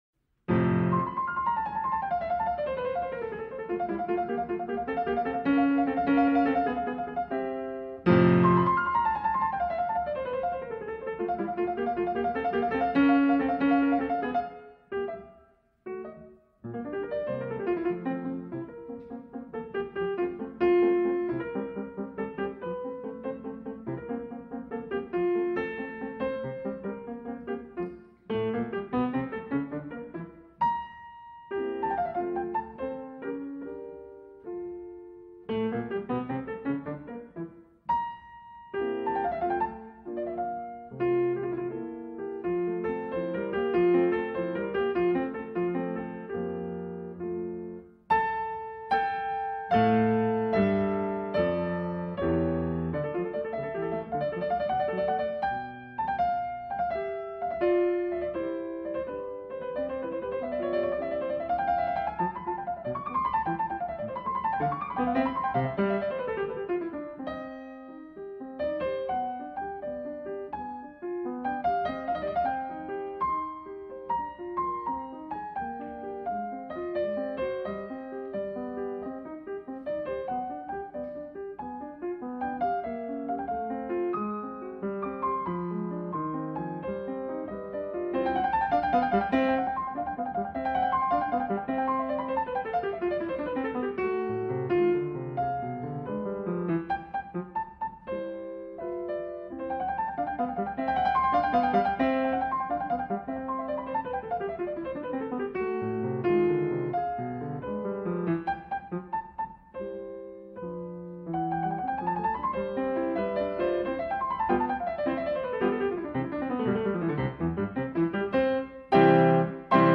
Venise Naturelle : Pluie Canal Focus